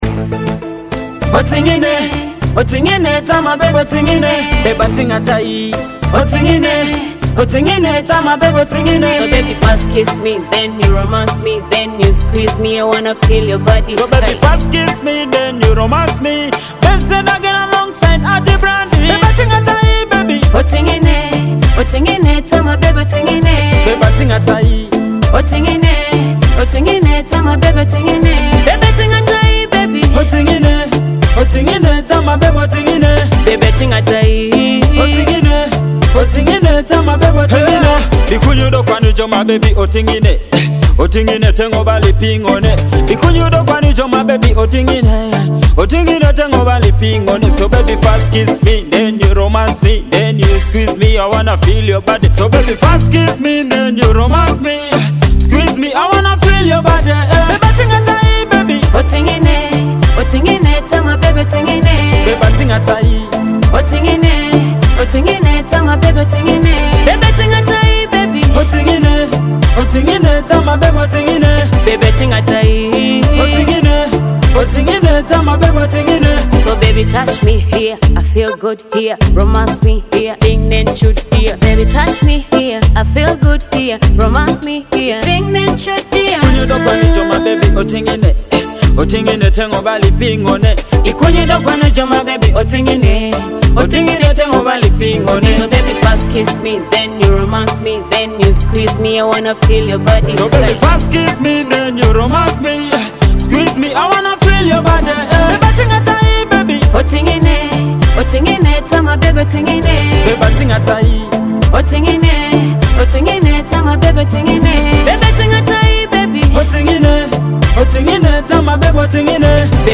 a smooth and emotional Teso romantic hit
love song
Romantic hit dance hall